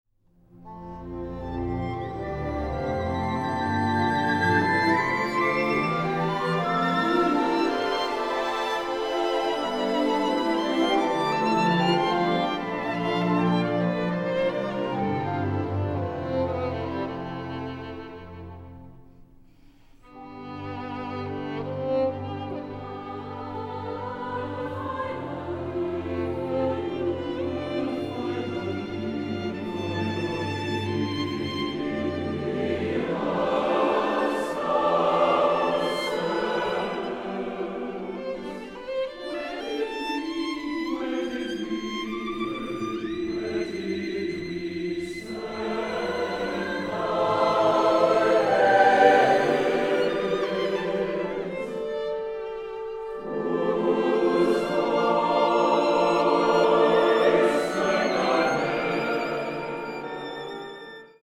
Instrumentation: solo violin, SATB chorus, orchestra